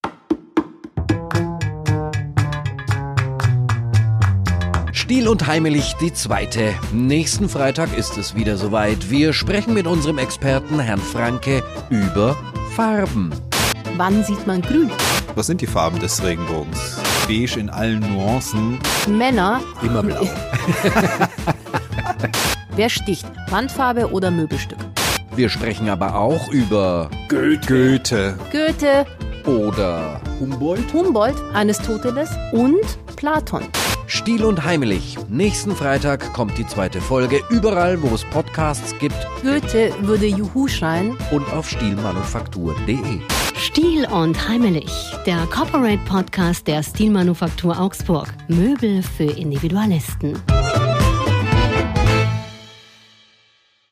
im gehobenen Small-Talk über Design,